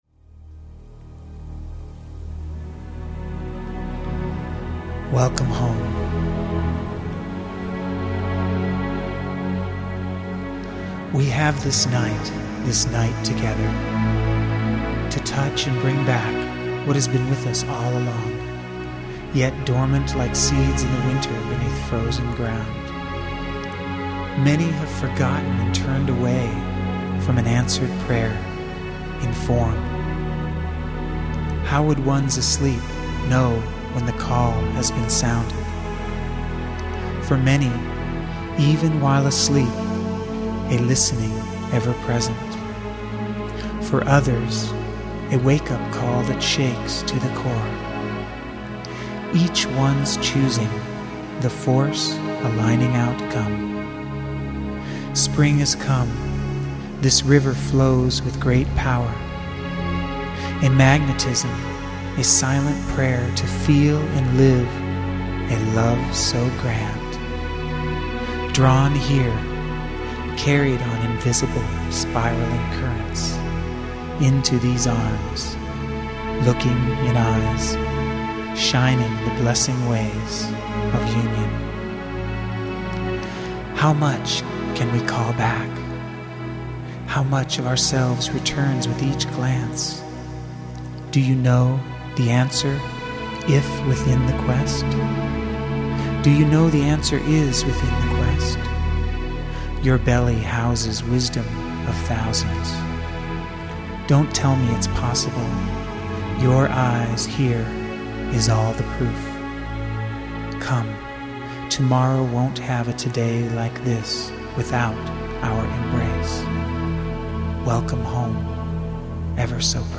Ecstatic Poetry & Music for the Lover in All...